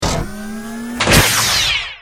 battlesuit_tinylaser.ogg